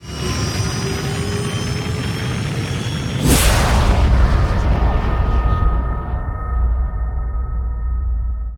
gravjump.ogg